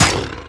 bitehit3.wav